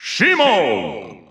The announcer saying Simon's name in Japanese and Chinese releases of Super Smash Bros. Ultimate.
Simon_Japanese_Announcer_SSBU.wav